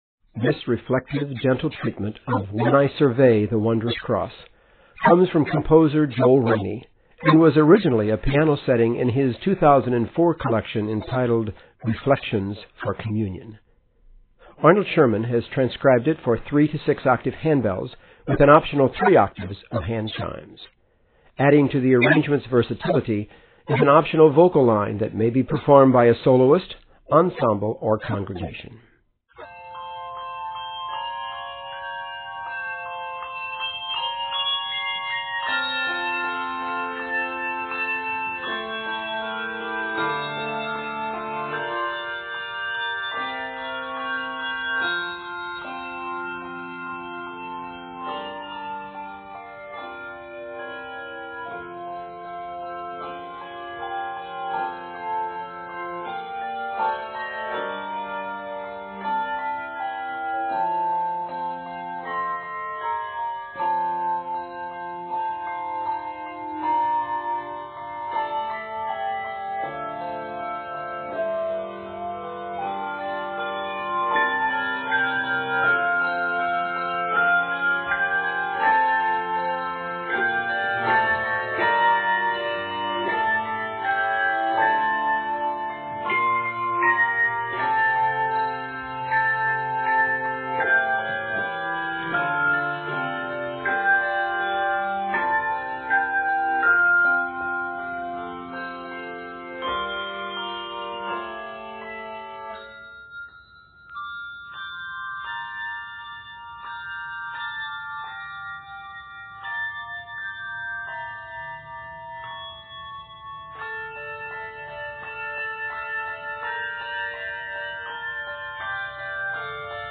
three to five octave handbells
gentle and reflective treatment